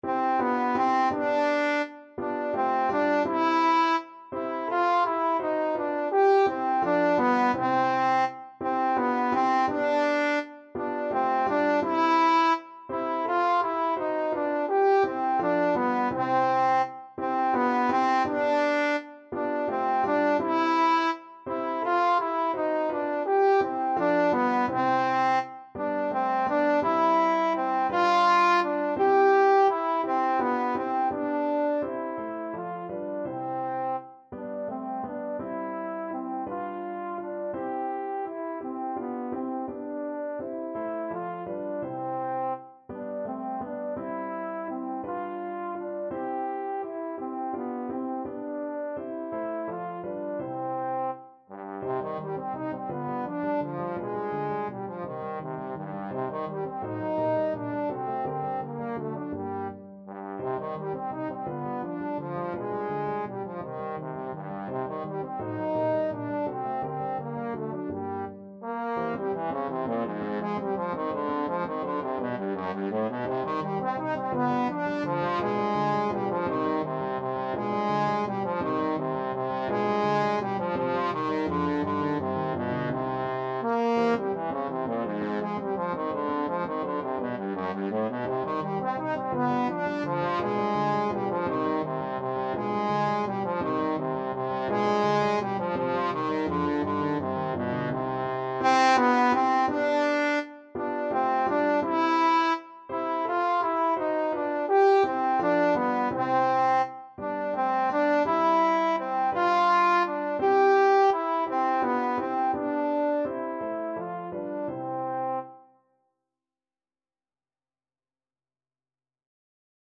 3/8 (View more 3/8 Music)
Lustig (Happy) .=56
Classical (View more Classical Trombone Music)